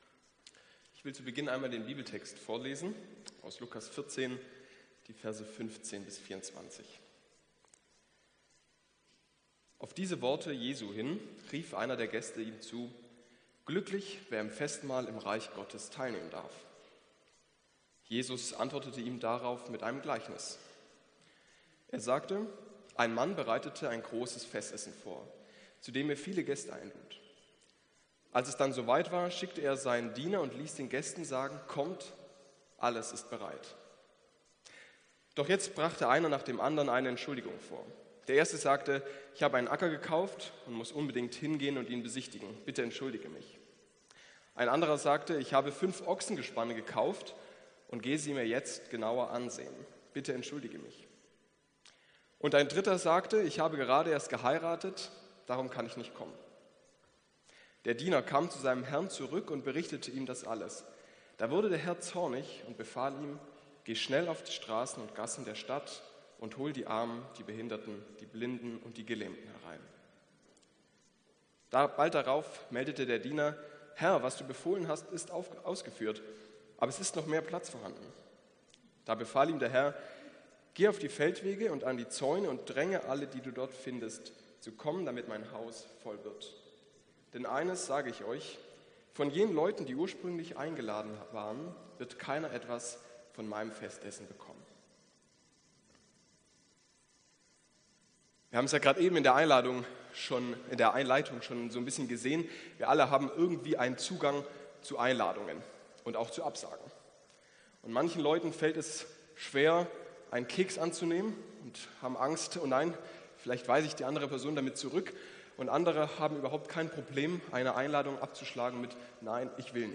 Predigt vom 28.04.2024